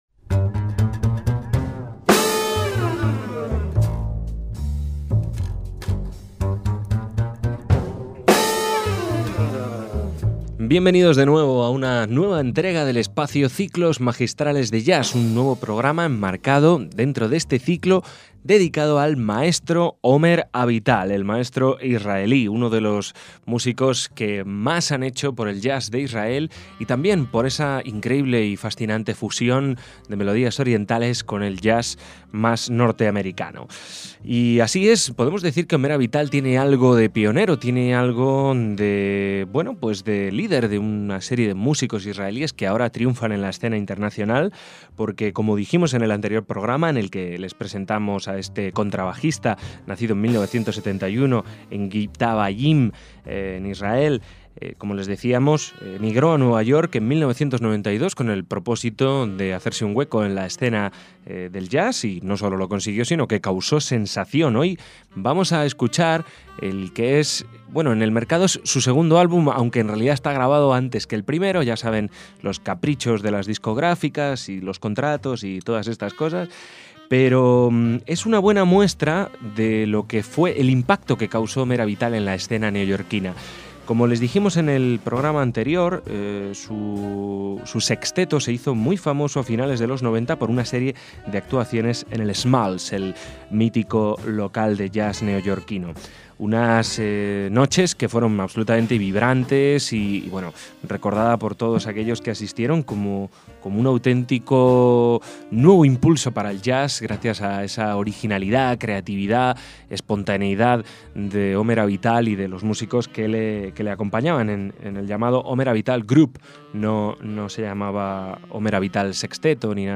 contrabajista israelí
un directo grabado en 1996
jazz